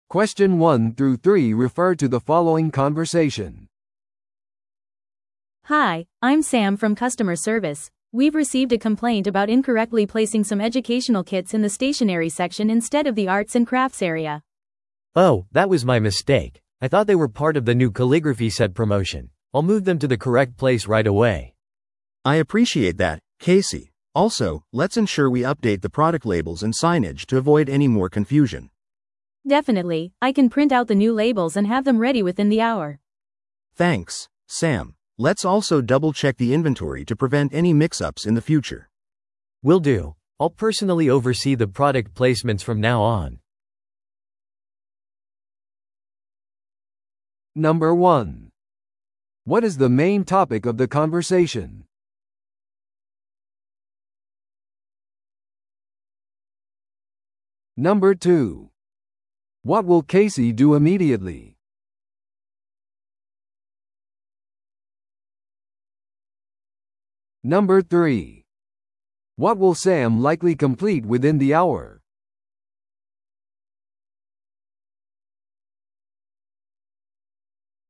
No.1. What is the main topic of the conversation?